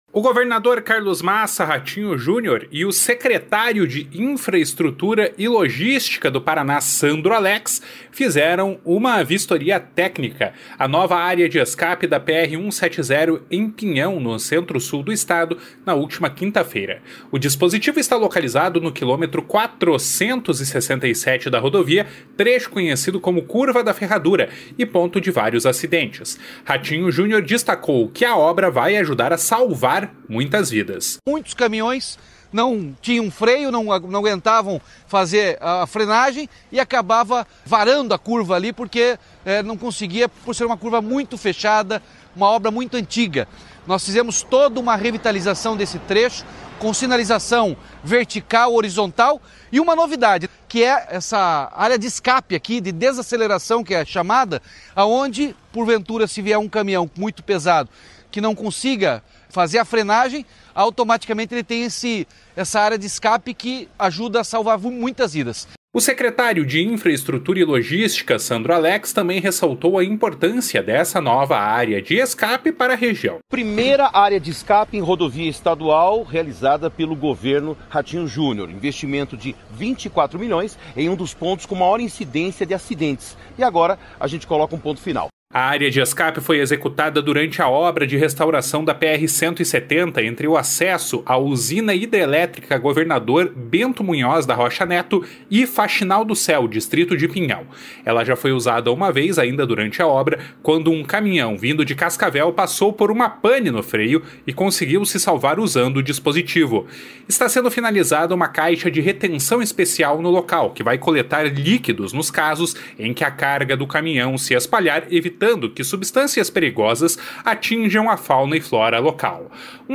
Ratinho Junior destacou que essa obra vai ajudar a salvar muitas vidas.
O secretário de Infraestrutura e Logística, Sandro Alex, também ressaltou a importância dessa nova área de escape para a região.